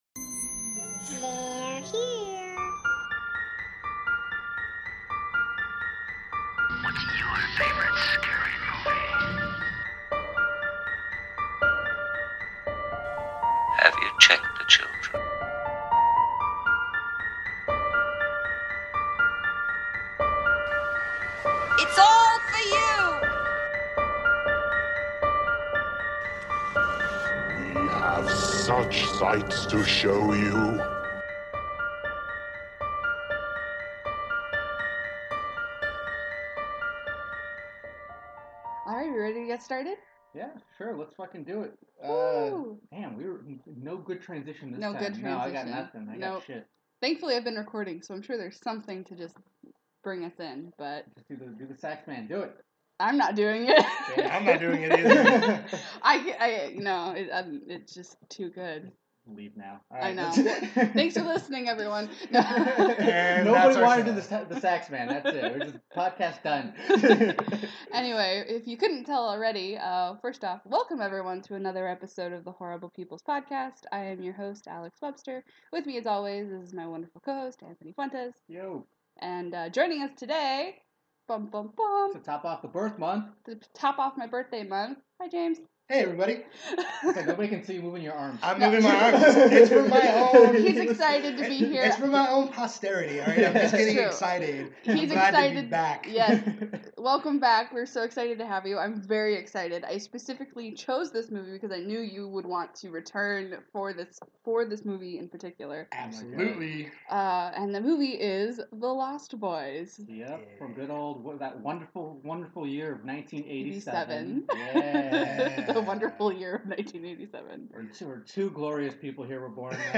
EDITORS NOTE: please forgive the sound issues this week, we had a few issues with the mic.